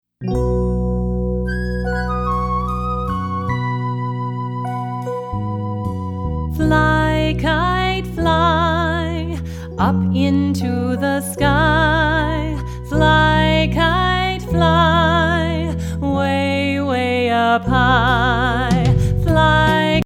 hand clappin', foot stompin' fun for everyone!